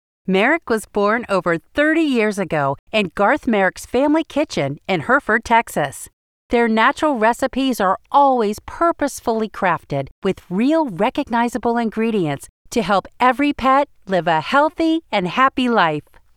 Merrick Ad
English-Mid Atlantic
Middle Aged